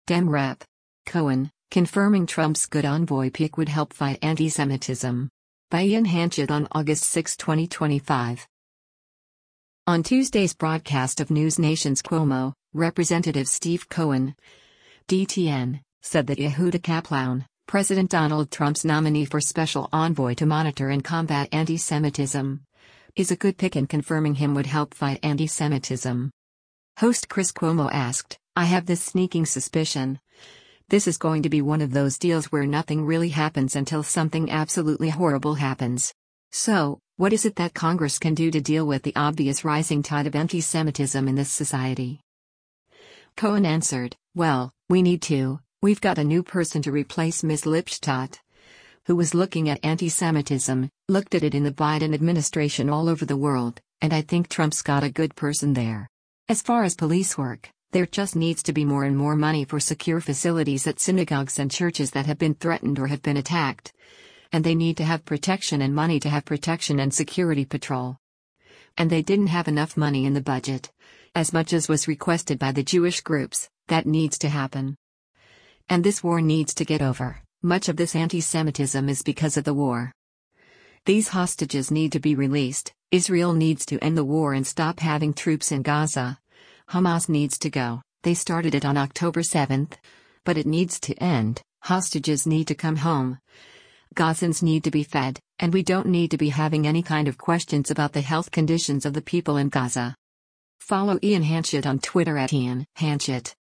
On Tuesday’s broadcast of NewsNation’s “Cuomo,” Rep. Steve Cohen (D-TN) said that Yehuda Kaploun, President Donald Trump’s nominee for Special Envoy to Monitor and Combat Antisemitism, is a good pick and confirming him would help fight antisemitism.